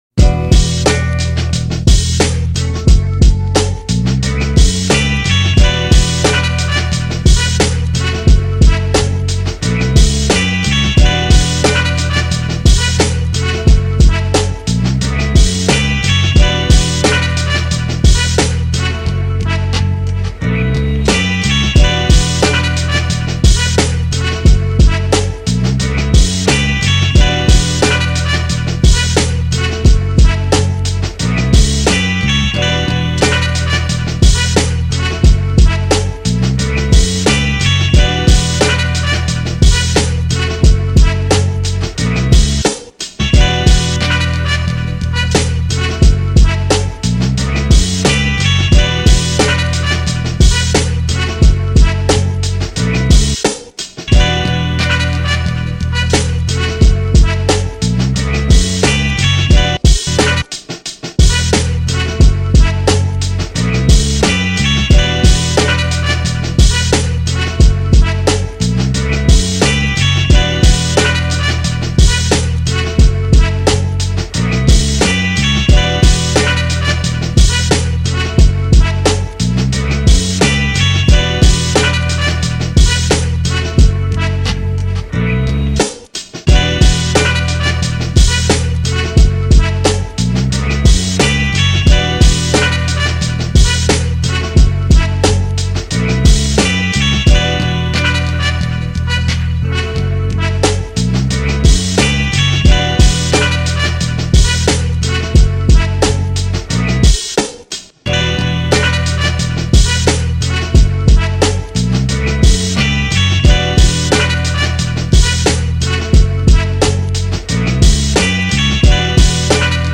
2024 in Boom Bap Instrumentals